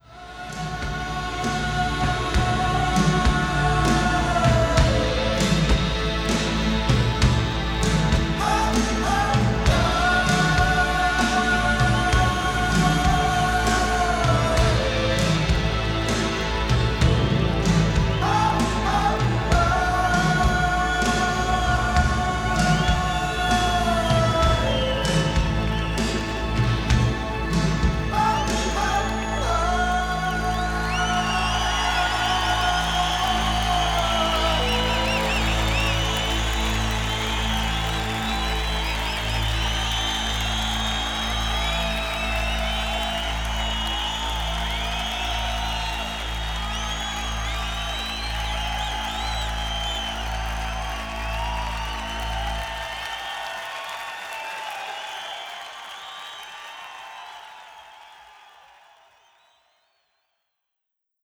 Compare Sample from original CD to newly Digital Refresh.